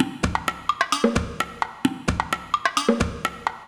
130_perc_2.wav